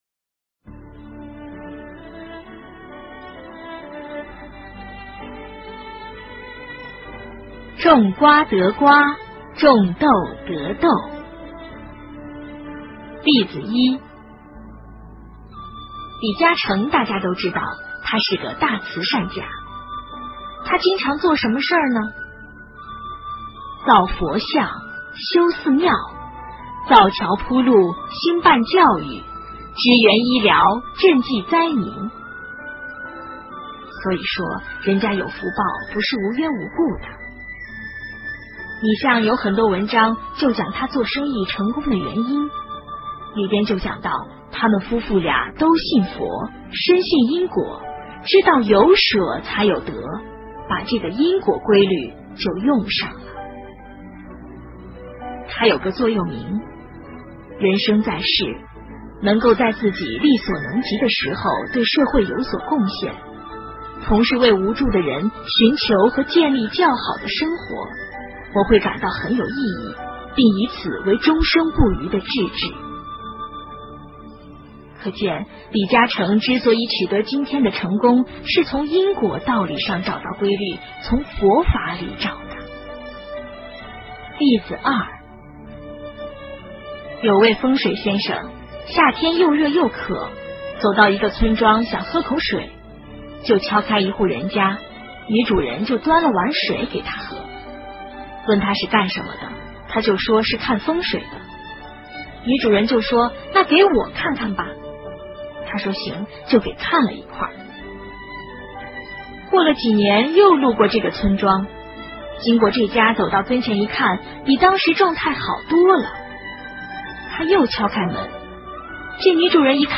种瓜得瓜 种豆得豆--有声佛书